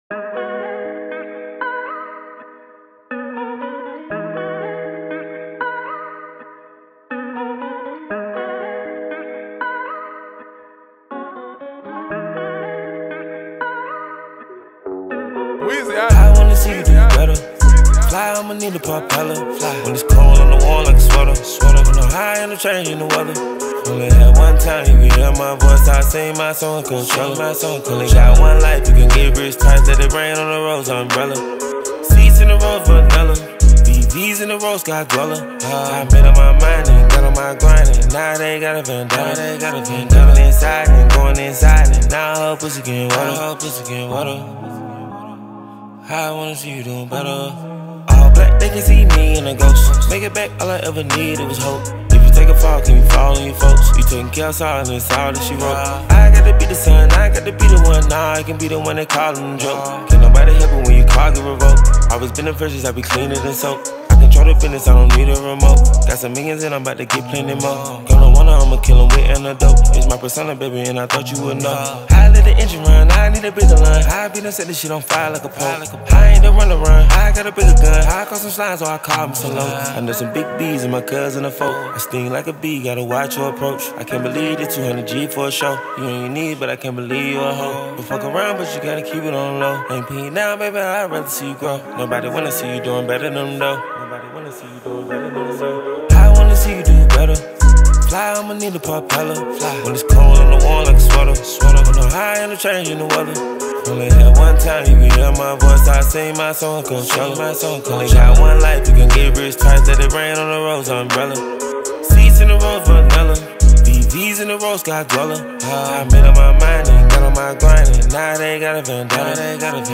Комбинируя мелодичный хип-хоп и душевные тексты